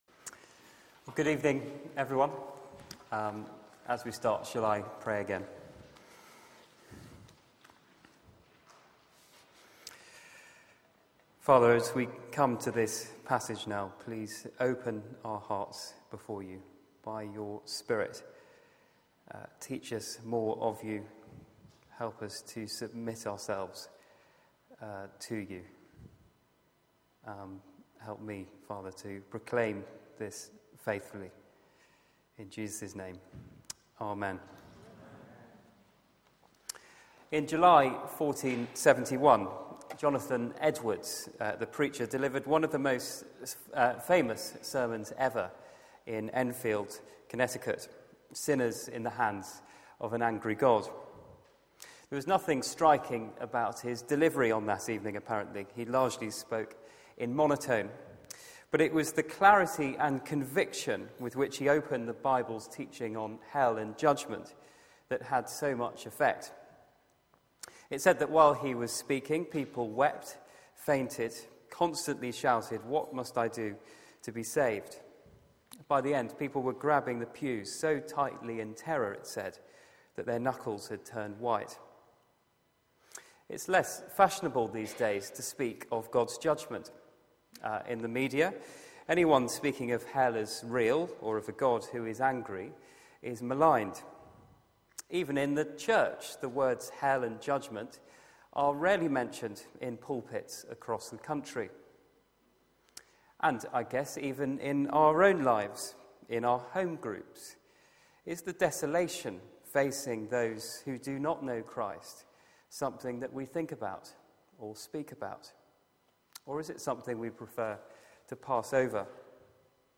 Media for 6:30pm Service on Sun 23rd Mar 2014 18:30 Speaker
Sermon Search the media library There are recordings here going back several years.